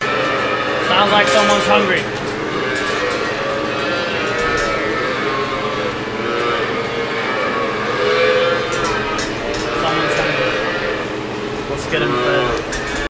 Sounds like someone is hungry sound effects free download